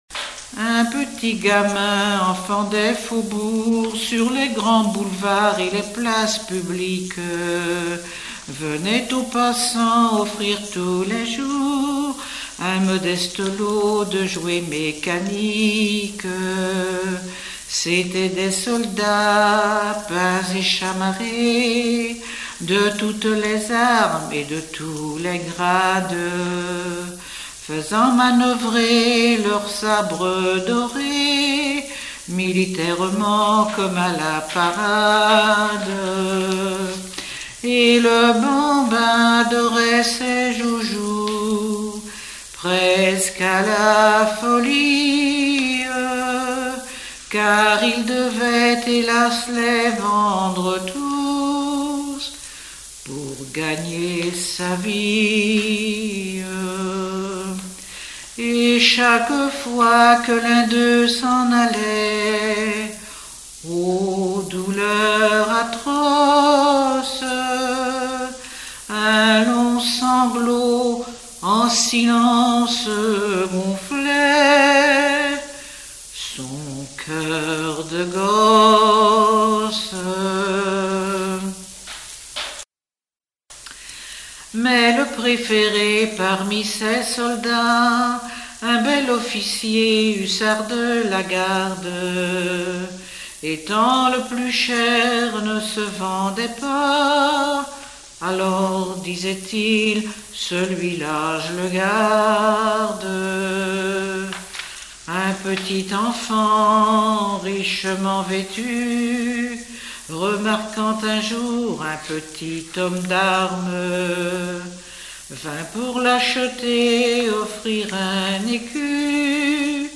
Chansons de variété
Pièce musicale inédite